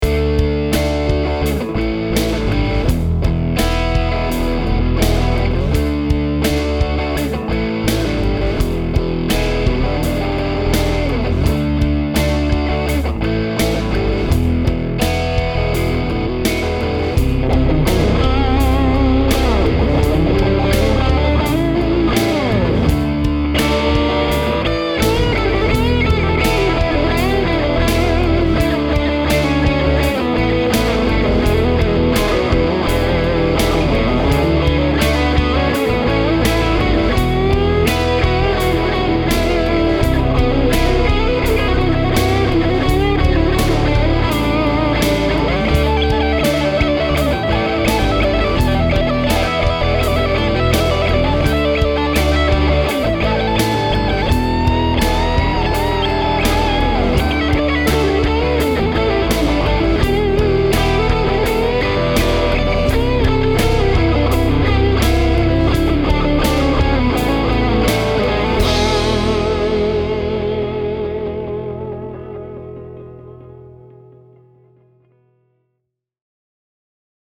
In any case, I recorded the rhythm part with the guitar plugged straight into the amp.
The amp was in the drive channel cranked all the way up. Also, the rhythm part was done with the guitar in the middle position, while the lead was on the bridge pickup.
At least to me, the end result is just pure, cranked Les Paul/Vintage Marshall tone. No distortion or overdrive pedals, just getting my distortion from gain.